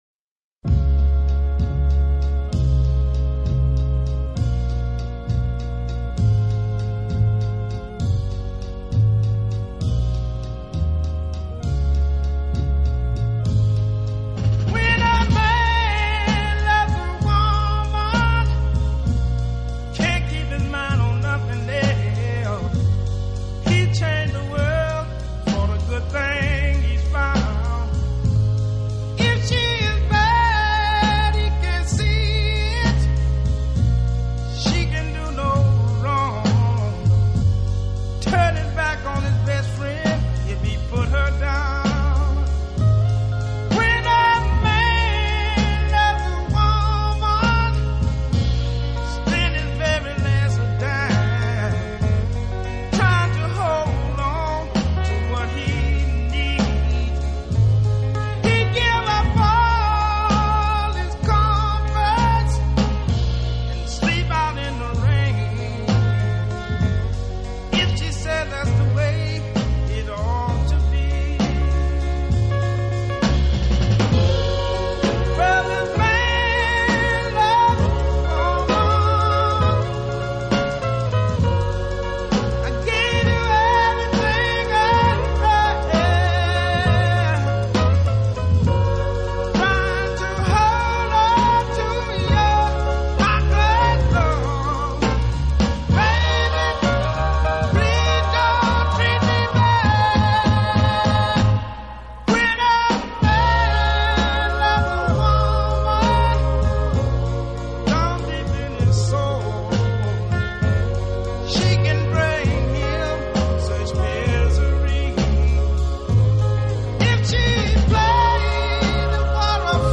当您在聆听浪漫音乐的时候，优美，舒缓的音乐流水一样缓缓抚过心田，你会觉